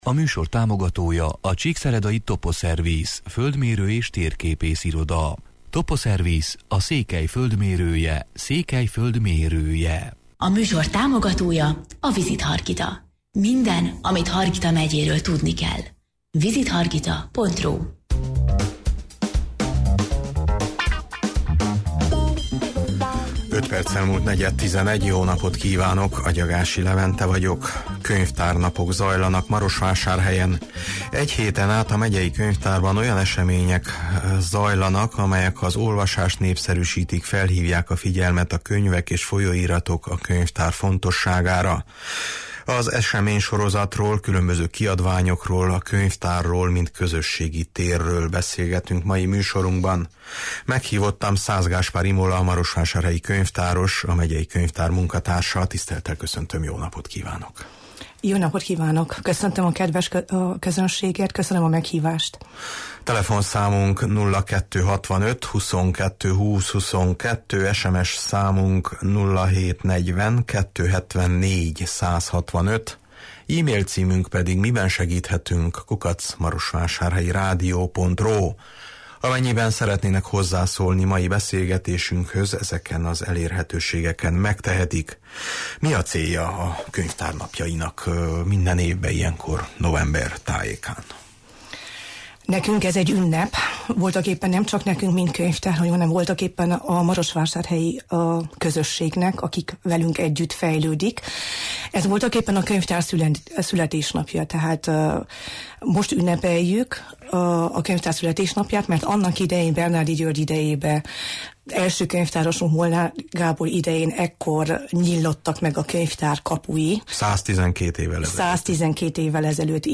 Az eseménysorozatról, különböző kiadványokról, a könyvtárról, mint közösségi térről beszélgetünk mai műsorunkban.